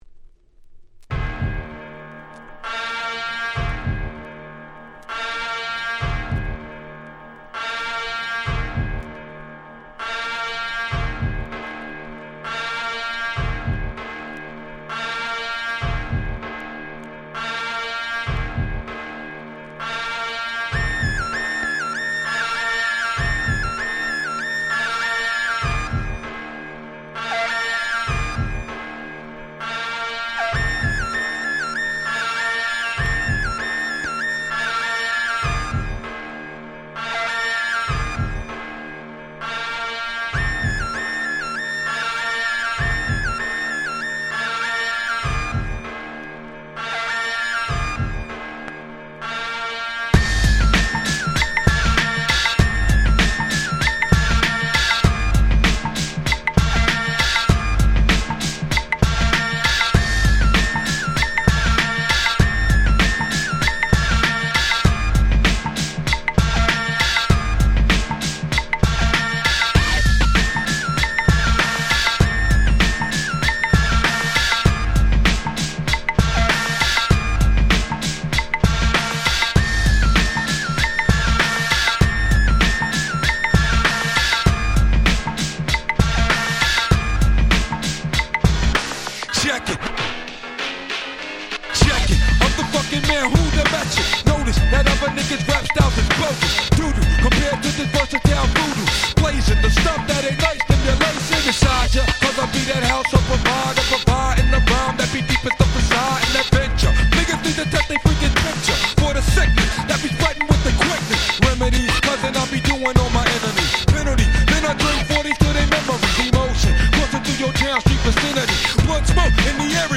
95' Hip Hop Classics !!